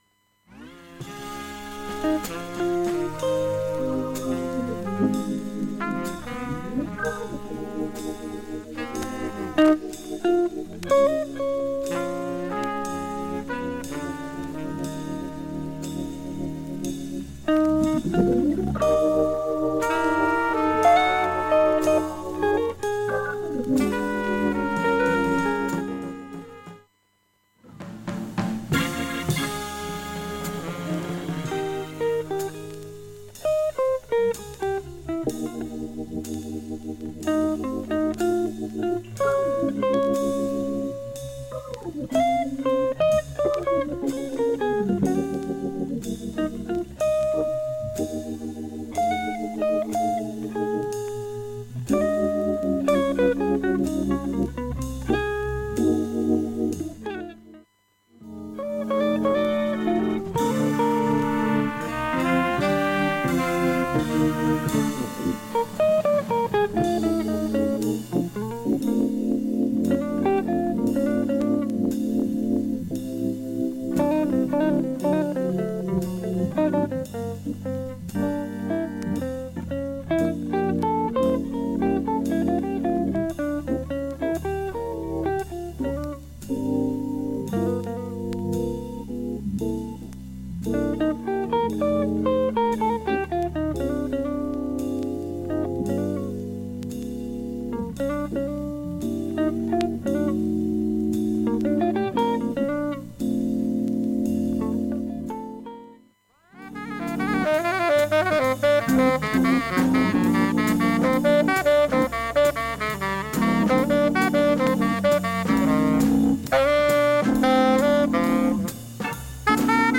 だいぶクリアな音質です。
A-1序盤にごくわずかなチッと3回音出ます。
現物の試聴（上記）できます。音質目安にどうぞ
◆ＵＳＡ盤オリジナル STEREO
ブルージーなソウル臭がプンプンするドス黒さと品を
兼備えた名作バラード集